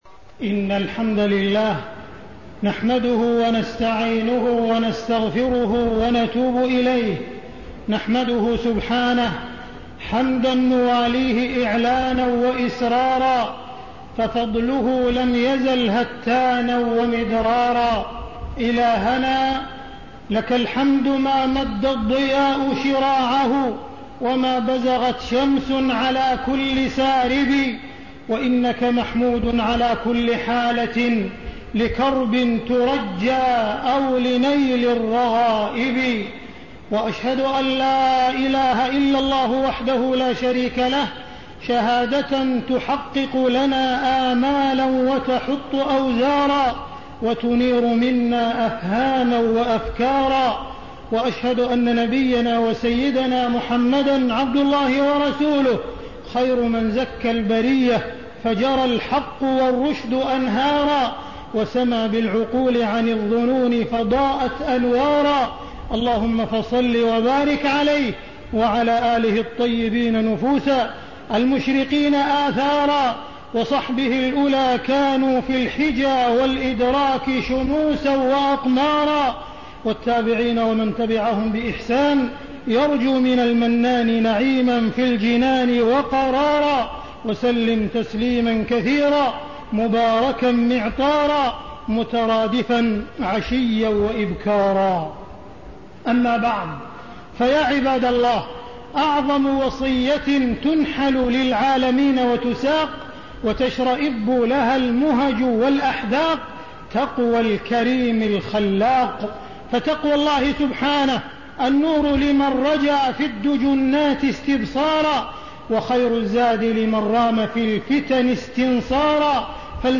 تاريخ النشر ١٤ ذو القعدة ١٤٣٤ هـ المكان: المسجد الحرام الشيخ: معالي الشيخ أ.د. عبدالرحمن بن عبدالعزيز السديس معالي الشيخ أ.د. عبدالرحمن بن عبدالعزيز السديس الفهم والإدراك